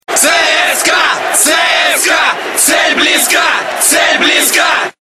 Футбольные кричалки